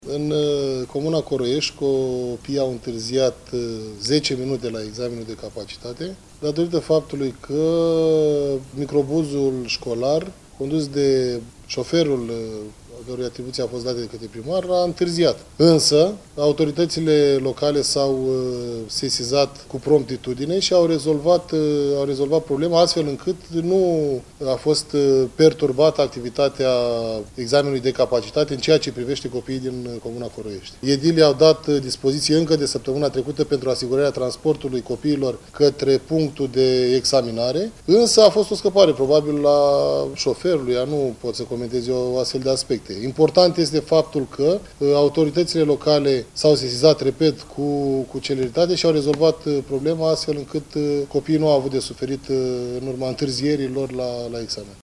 Incidentul este unul neplăcut, a declarat prefectul de Vaslui, Eduard Popică.